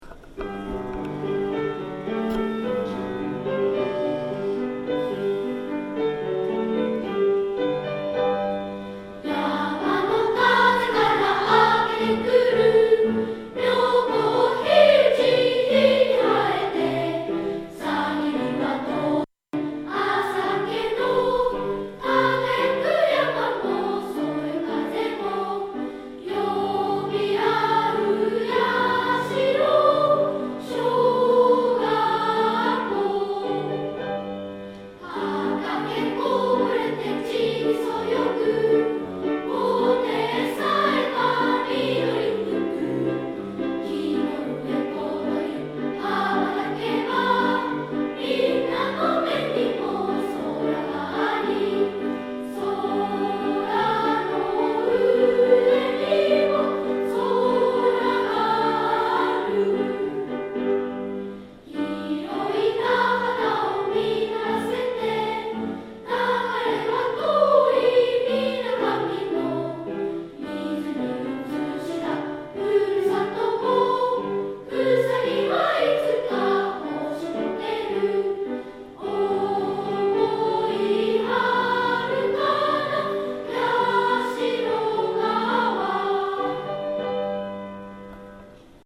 矢代小学校校歌を録音しました。歌っているのは全校児童70人です　3/23 227